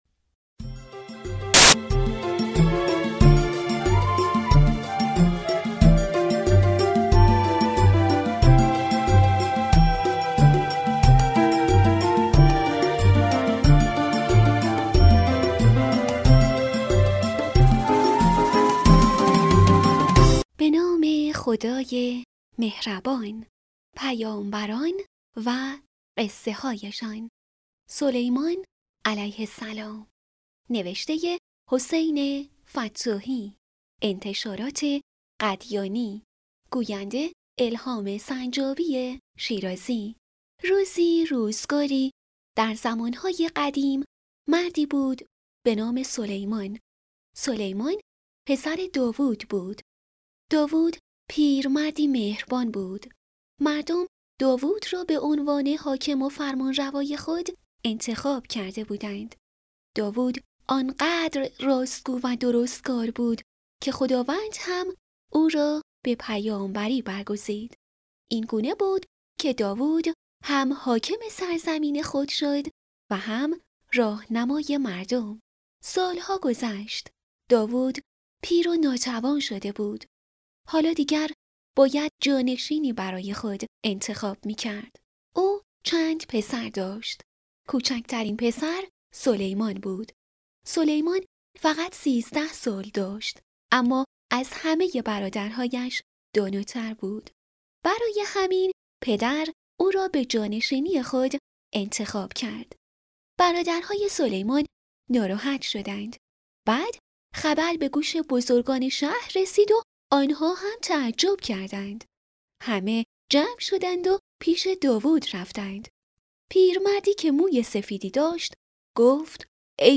دانلود صوت بفرمایید قصه کتاب صوتی «پیامبران و قصه‌هایشان» این قسمت سلیمان علیه السلام راوی